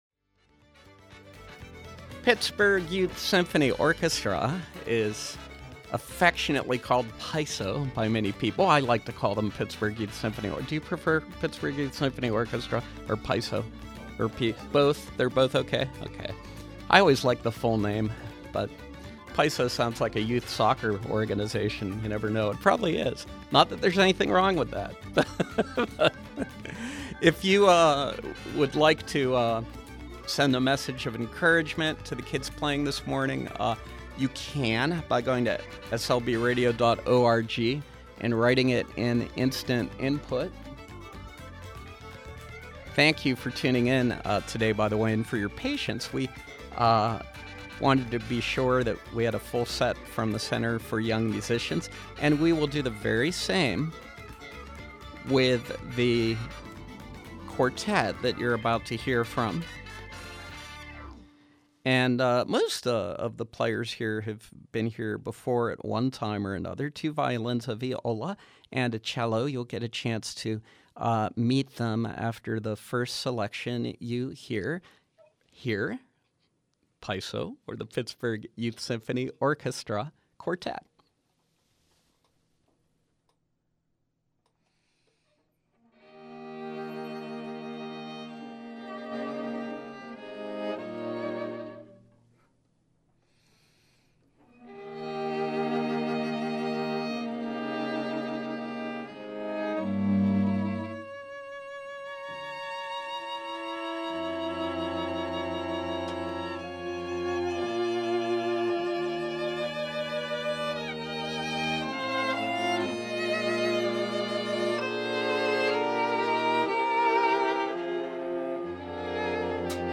From 5/18/13: A string quartet featuring members of the Pittsburgh Youth Symphony Orchestra